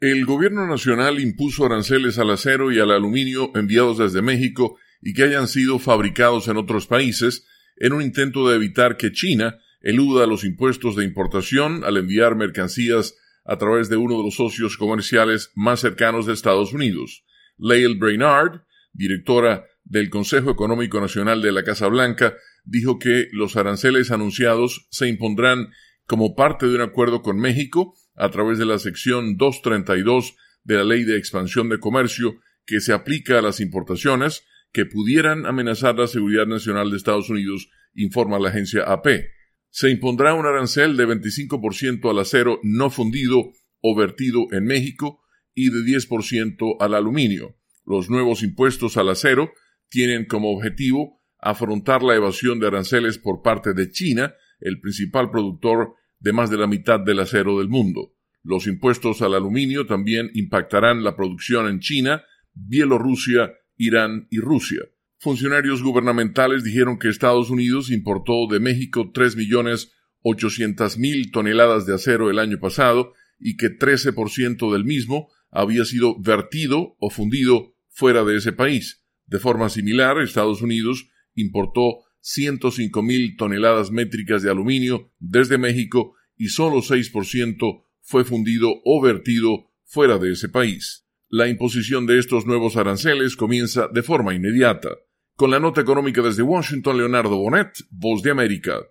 desde la Voz de América, en Washington.